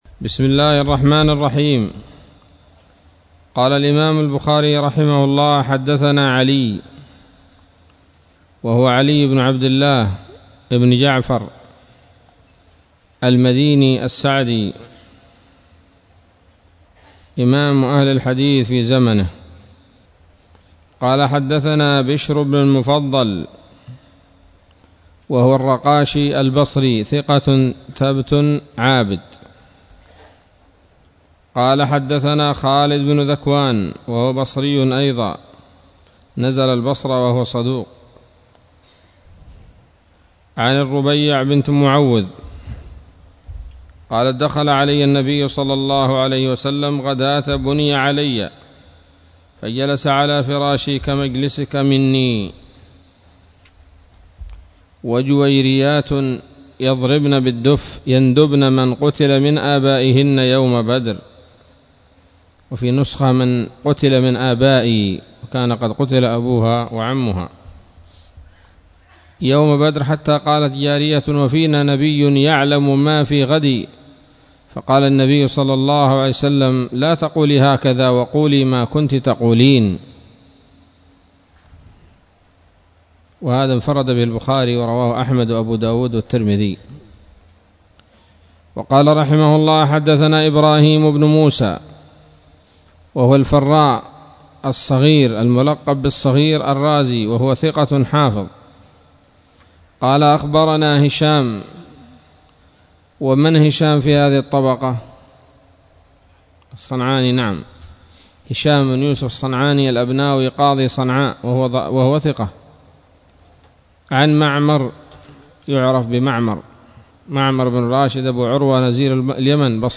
الدرس التاسع عشر من كتاب المغازي من صحيح الإمام البخاري